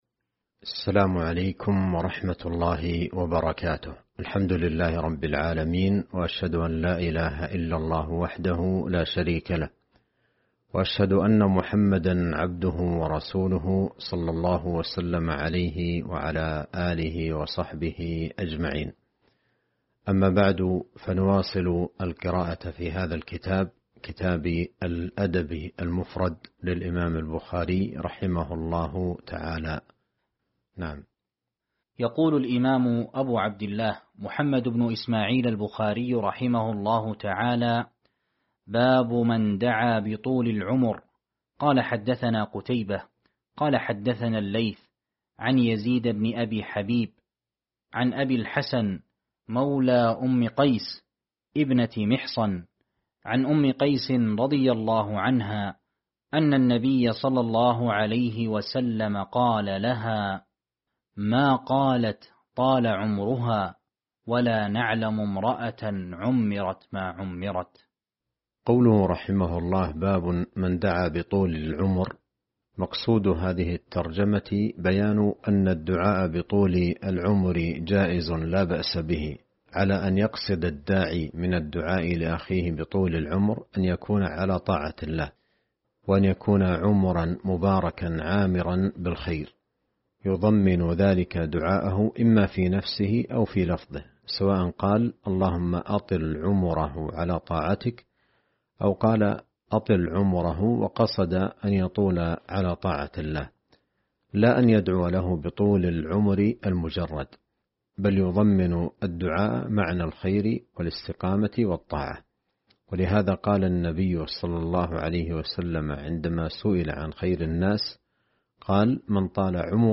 شرح الأدب المفرد الدرس 206 باب من دعا بطول العمر